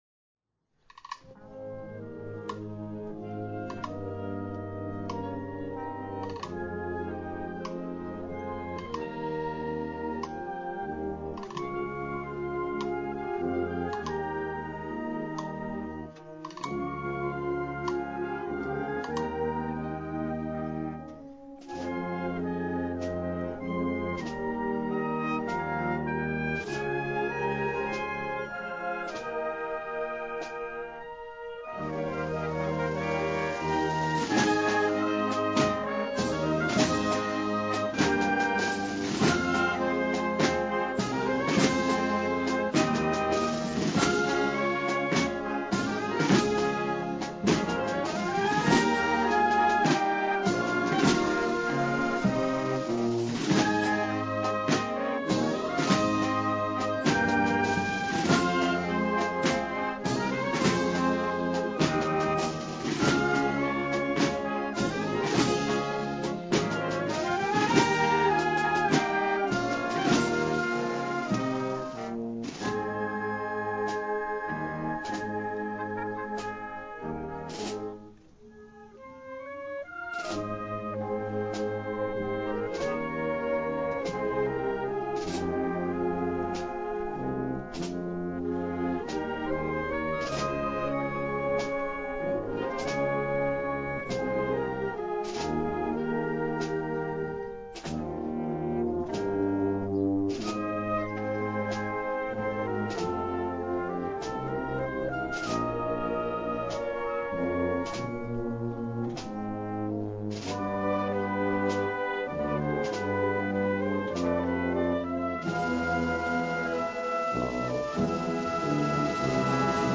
Marchas dedicadas a María Santísima del Auxilio